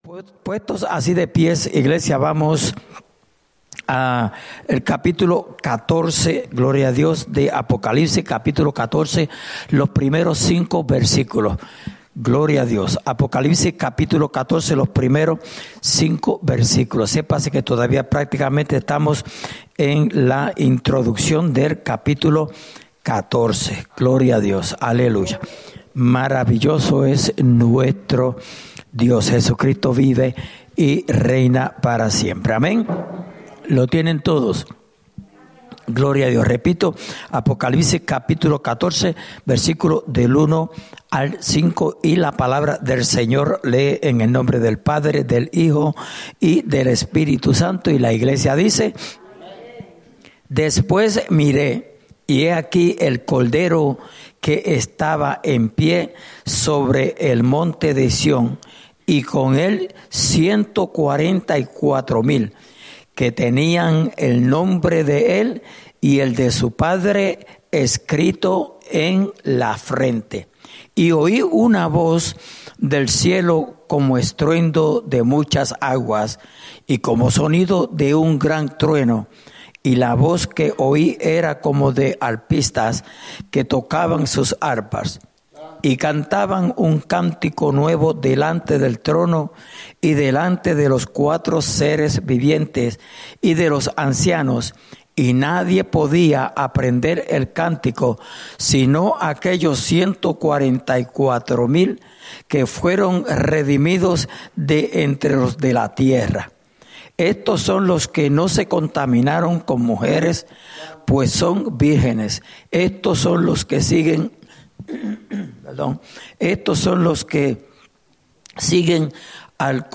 Estudio Bíblico: Libro de Apocalipsis (Parte 25)